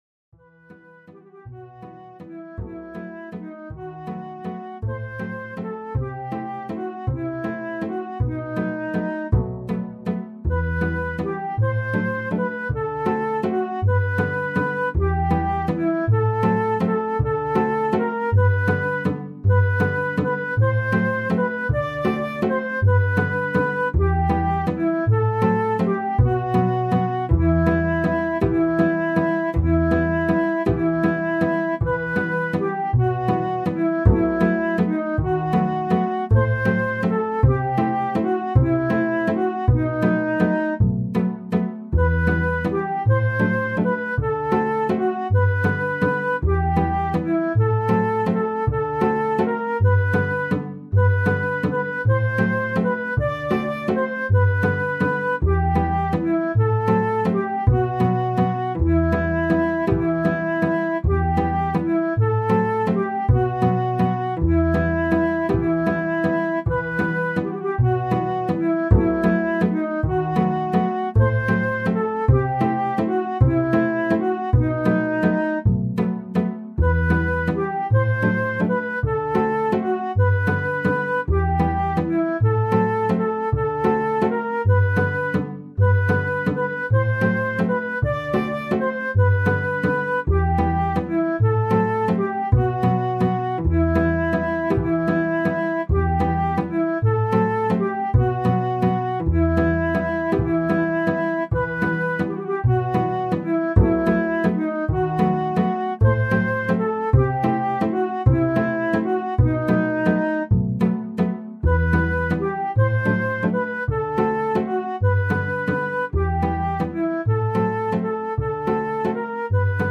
Mehrstimmige Melodien mit wundersamen Klängen